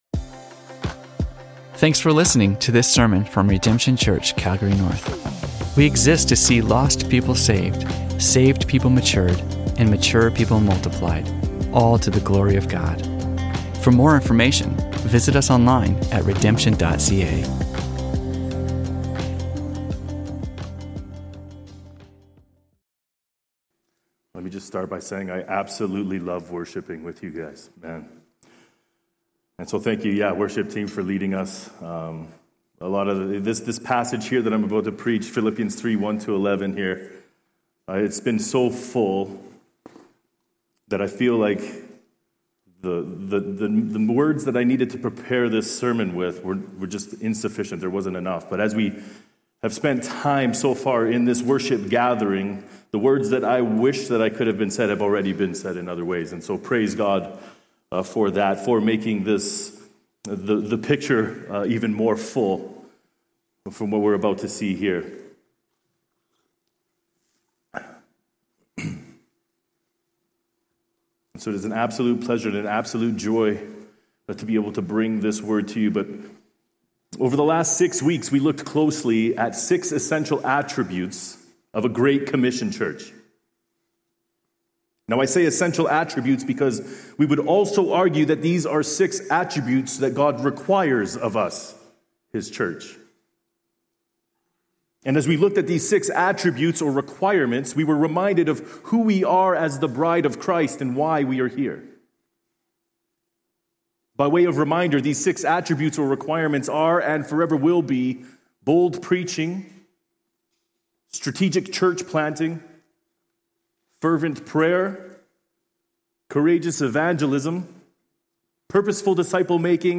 Religion Christianity Harvest Sermons Calgary Canada Redemption Church Calgary North Redemption Church Content provided by Redemption Church Calgary North and Redemption Church.